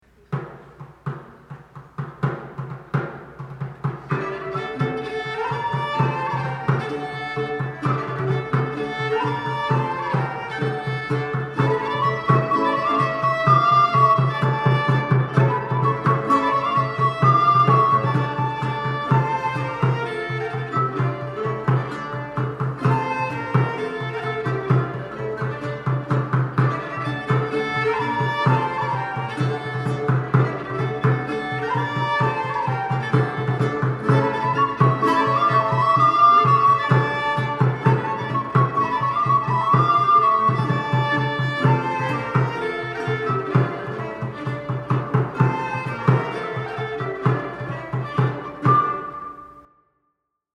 A lively dance from the Leutschauer Tabulaturbuch of 1676.